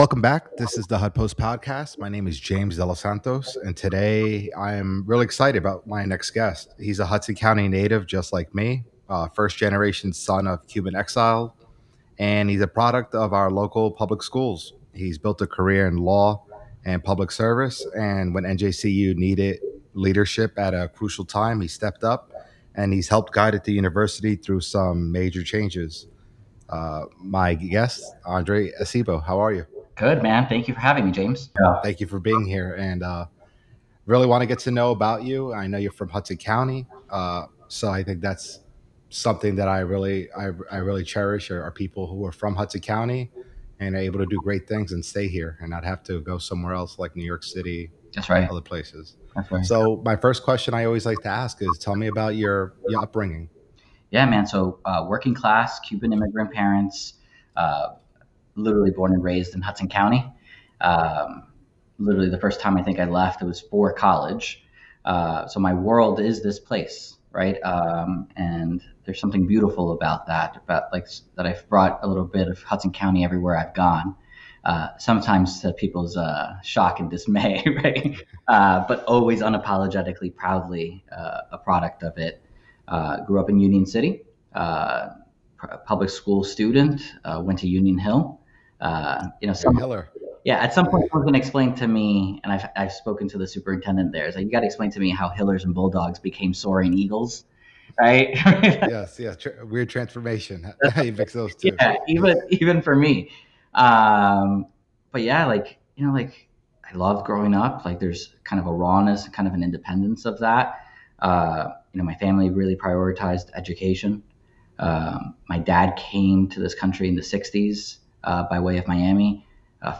This podcast interview